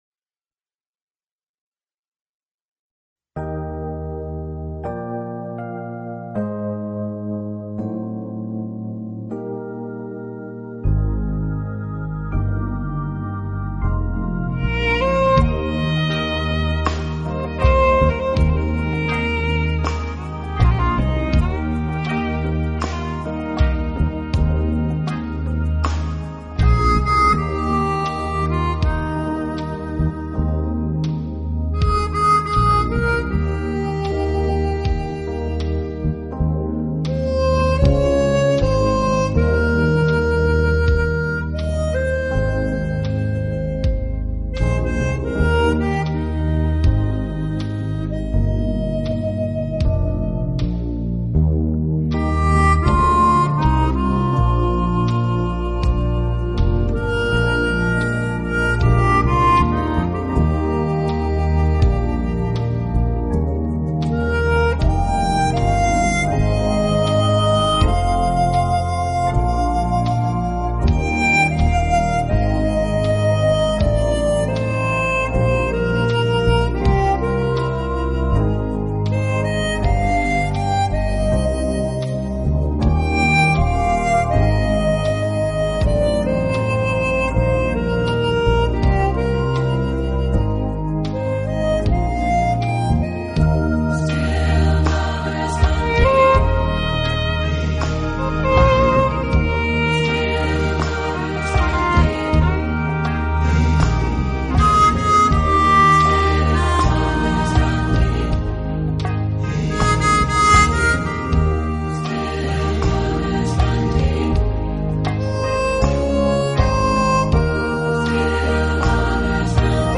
【纯音口琴】
同时其录音又极其细致、干净、层次
分明，配器简洁明了，是近年唱片市场上难得的一套西洋音乐唱片。